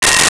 Sci Fi Beep 08
Sci Fi Beep 08.wav